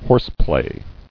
[horse·play]